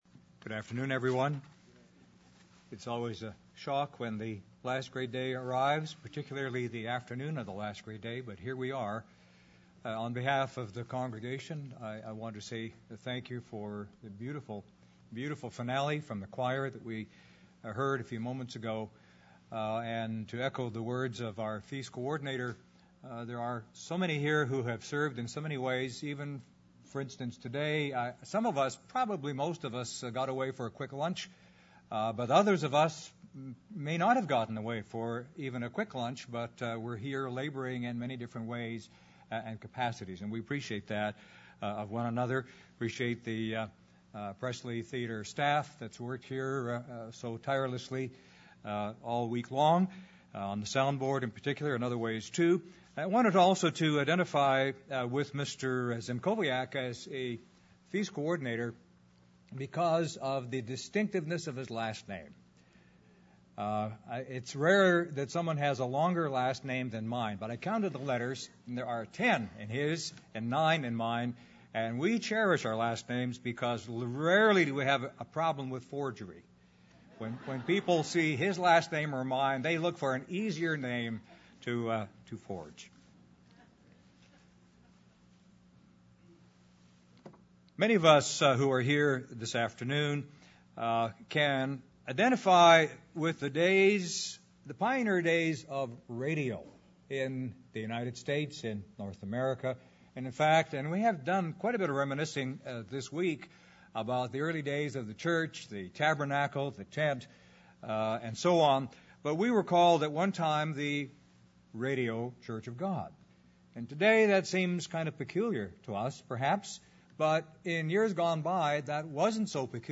This sermon was given at the Branson, Missouri 2015 Feast site.